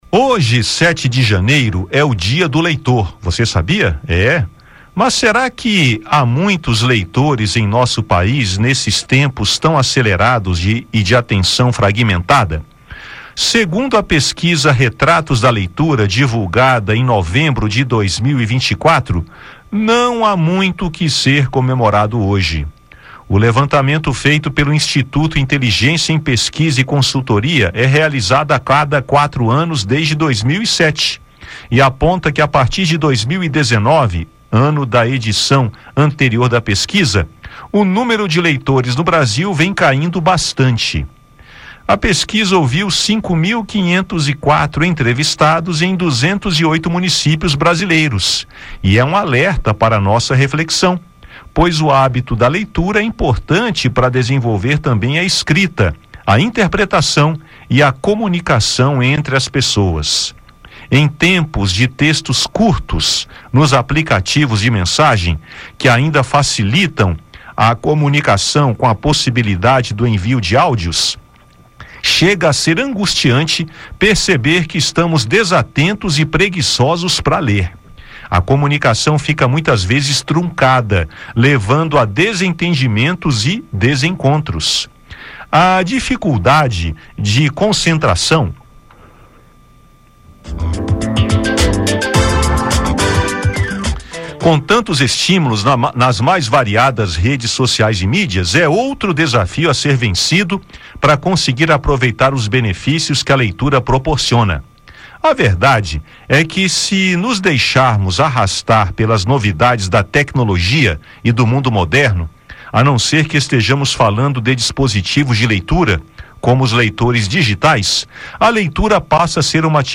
E neste 7 de janeiro, Dia do Leitor, aproveitamos para bater um papo sobre o assunto e refletir sobre o que influencia esse quadro. O mais importante é incentivarmos o hábito da leitura, tão essencial para a formação do ser humano e o desenvolvimento da criatividade, da escrita, da interpretação e da comunicação.